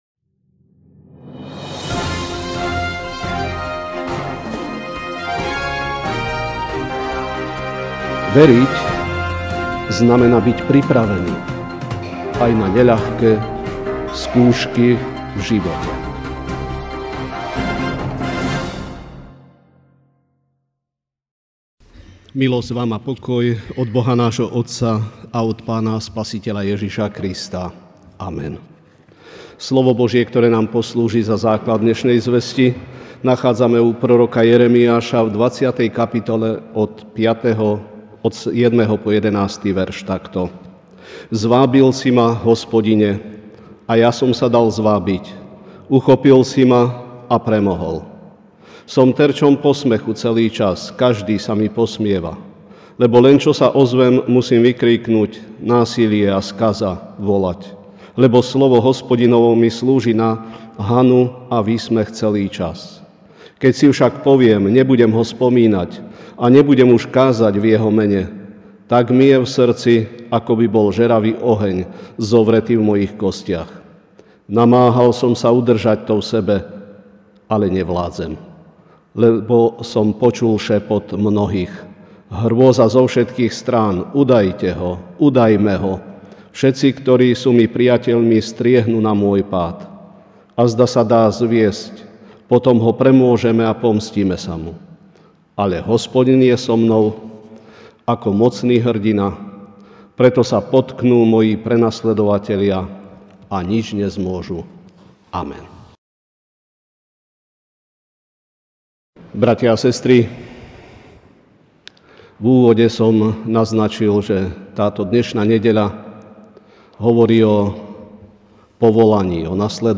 Večerná kázeň: Nasledovanie != Pohodlie (Jer 20, 7-11a) Zvábil si ma, Hospodine, a ja som sa dal zvábiť, uchopil si ma a premohol.